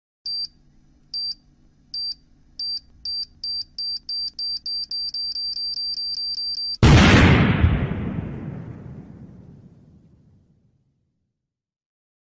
bomb sound effect no copyright sound effects free download